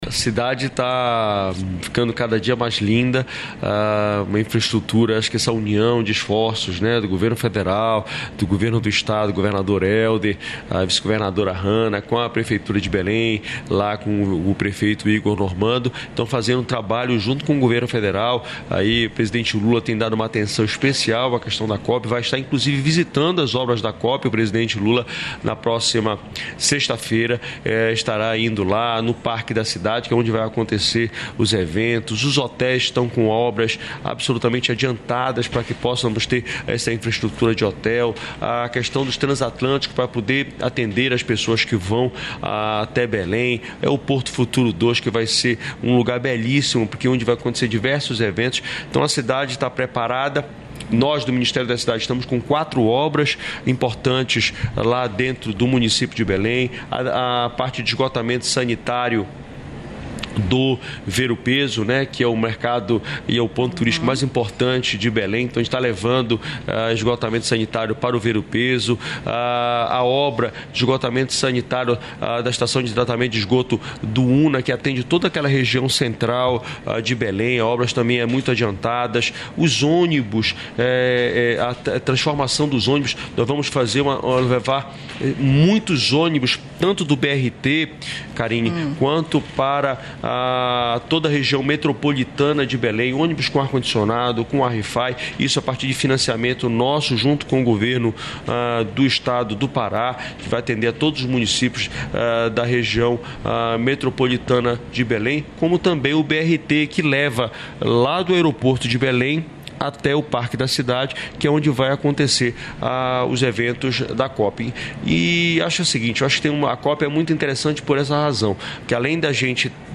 Trecho da participação do ministro das Cidades, Jader Filho, no programa "Bom Dia, Ministro" desta quarta-feira (12), nos estúdios da EBC no Encontro de Novos Prefeitos e Prefeitas, em Brasília.